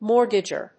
音節mort・gag・er 発音記号・読み方
/mˈɔɚgɪdʒɚ(米国英語), mˈɔːgɪdʒə(英国英語)/